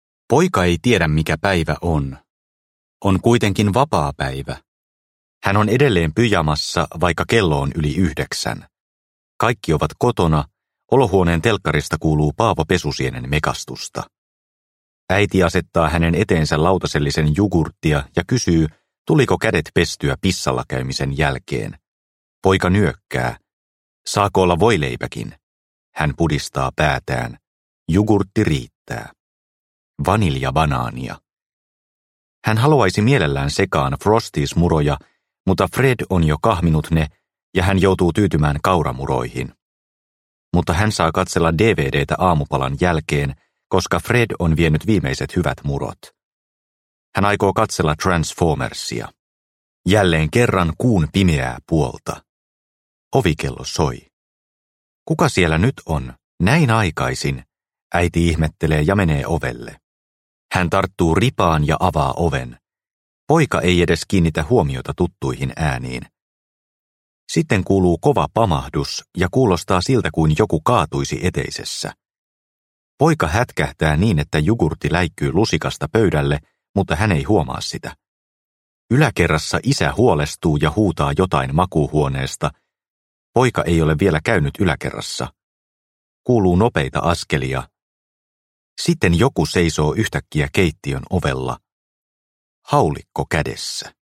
Mykkä tyttö – Ljudbok – Laddas ner